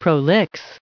1805_prolix.ogg